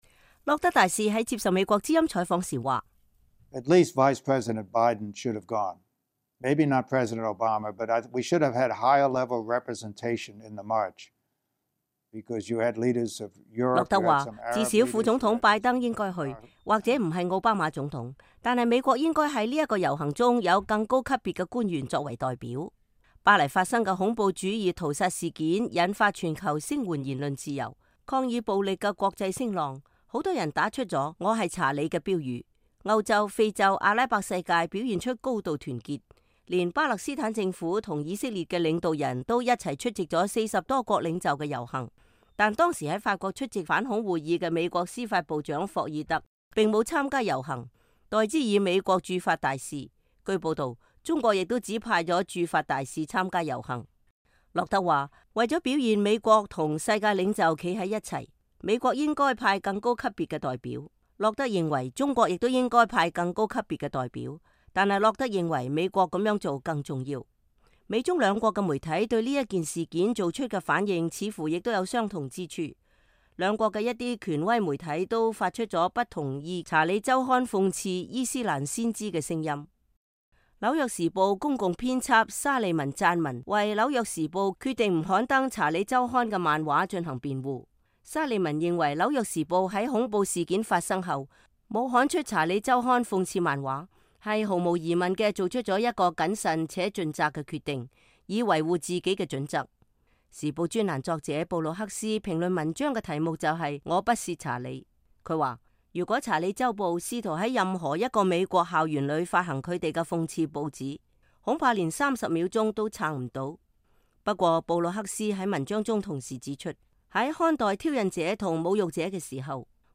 前美國東亞助卿洛德接受美國之音採訪